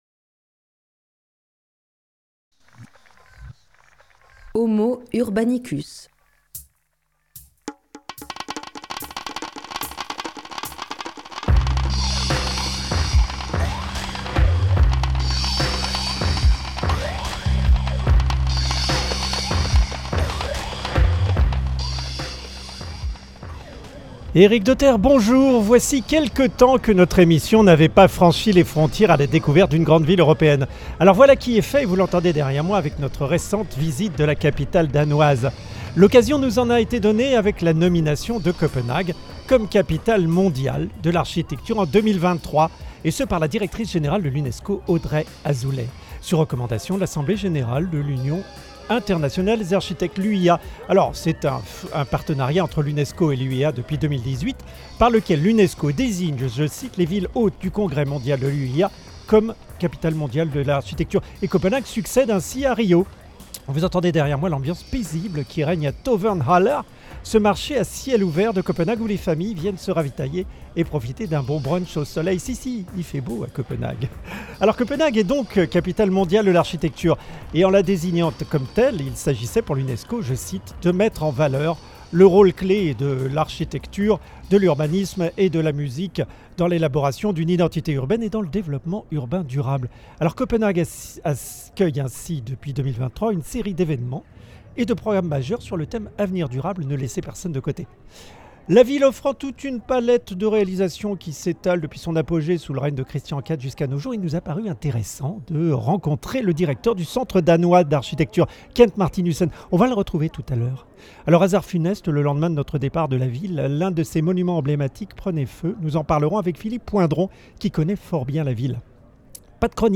Homo Urbanicus était ce lundi 24 juin 2024 en reportage à Copenhague.